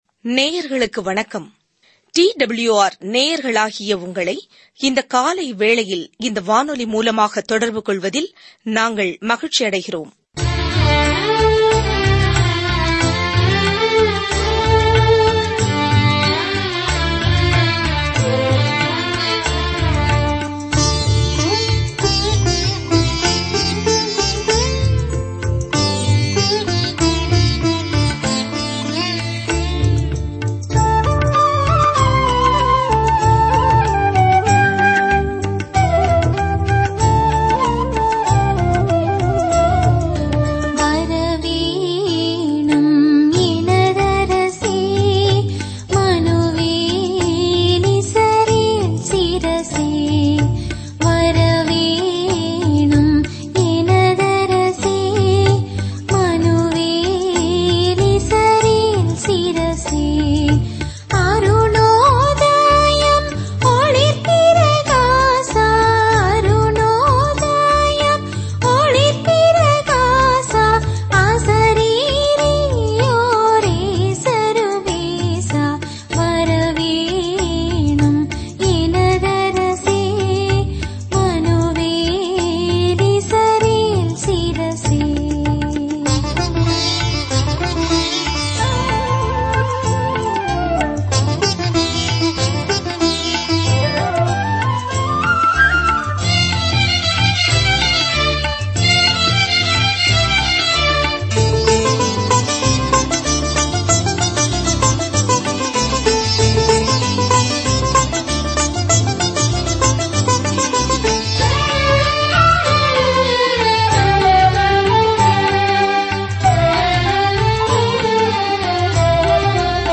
வேதவசனங்கள் மத்தேயு 8:19-34 நாள் 15 இந்த திட்டத்தை ஆரம்பியுங்கள் நாள் 17 இந்த திட்டத்தைப் பற்றி அவருடைய வாழ்க்கையும் ஊழியமும் பழைய ஏற்பாட்டு தீர்க்கதரிசனத்தை எவ்வாறு நிறைவேற்றியது என்பதைக் காண்பிப்பதன் மூலம் யூத வாசகர்களுக்கு இயேசு அவர்களின் மேசியா என்பதை மத்தேயு நிரூபிக்கிறார். நீங்கள் ஆடியோ படிப்பைக் கேட்கும்போதும் கடவுளுடைய வார்த்தையிலிருந்து தேர்ந்தெடுக்கப்பட்ட வசனங்களைப் படிக்கும்போதும் மத்தேயு வழியாக தினசரி பயணம் செய்யுங்கள்.